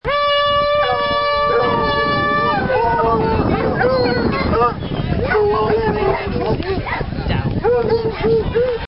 Horn Hunt Sound Button - Free Download & Play